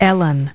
Transcription and pronunciation of the word "ellen" in British and American variants.